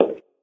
inside-step-4.ogg.mp3